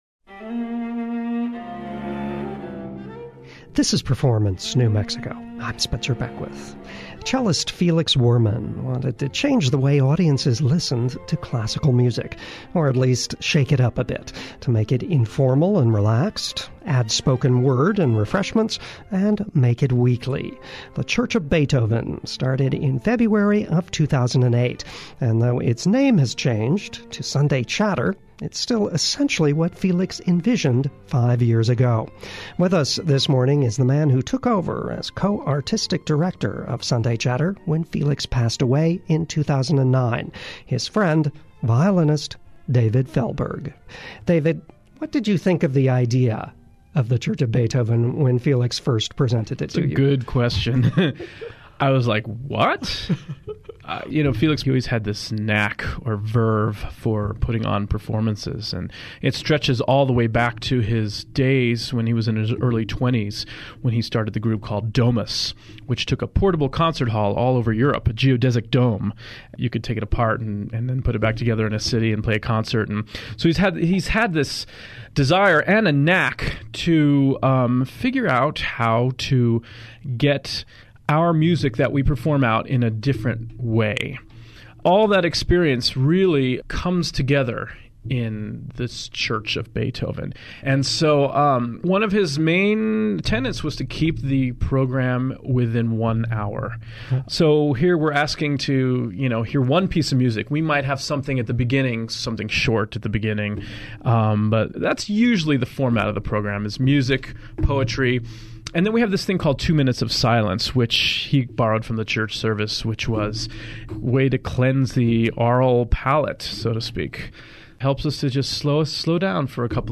interview on Performance New Mexico.